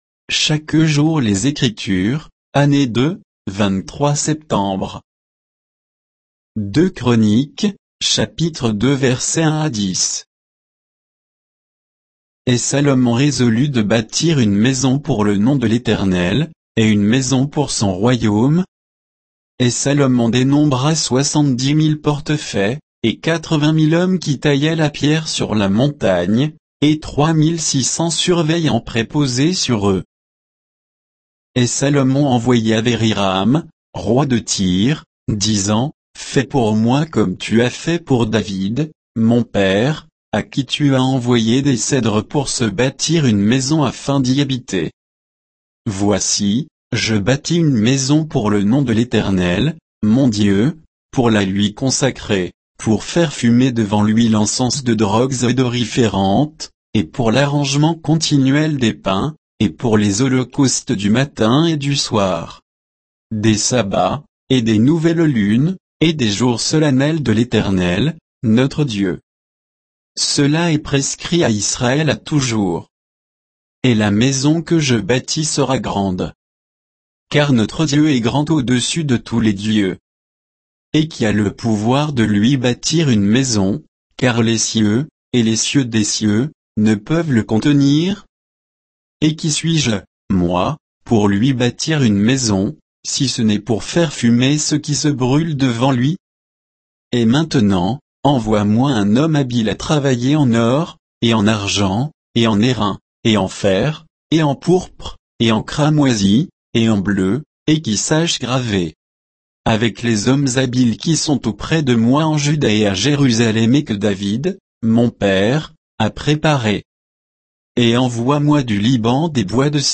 Méditation quoditienne de Chaque jour les Écritures sur 2 Chroniques 2, 1 à 10